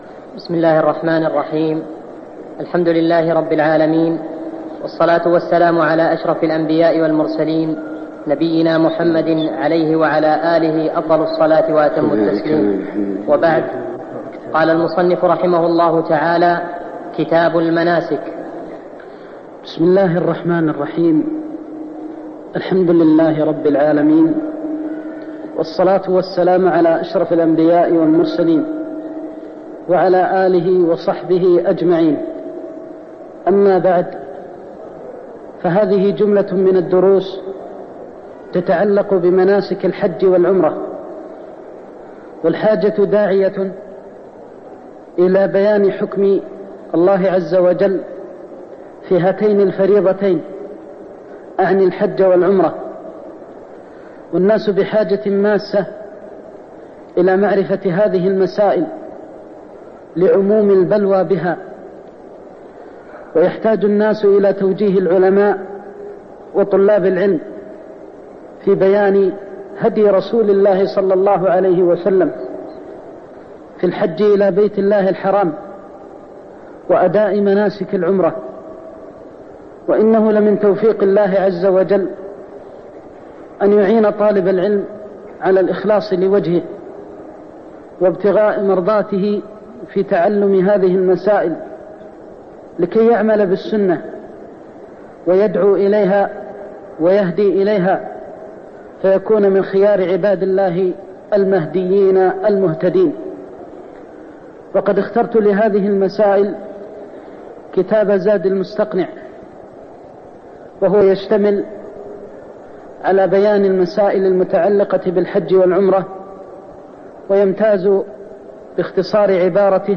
تاريخ النشر ١١ شوال ١٤١٧ هـ المكان: المسجد النبوي الشيخ: فضيلة الشيخ د. محمد بن محمد المختار فضيلة الشيخ د. محمد بن محمد المختار المقدمة (01)